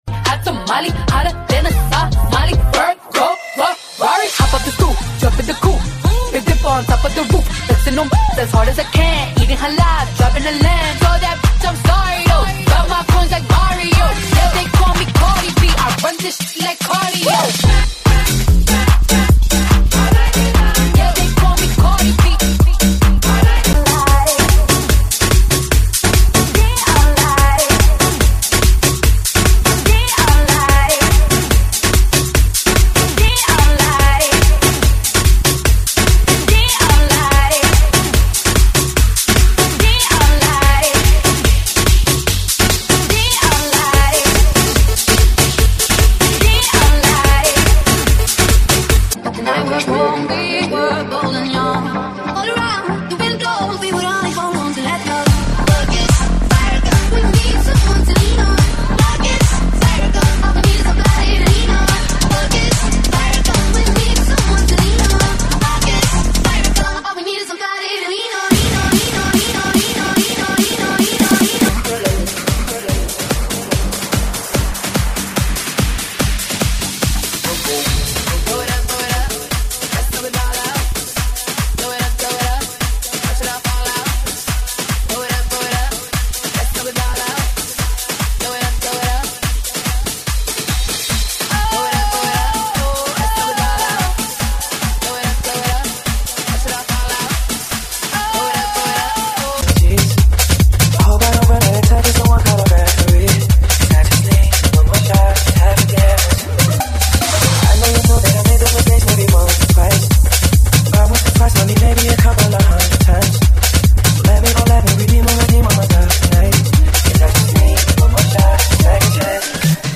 GENERO: ELECTRO, INGLES, POP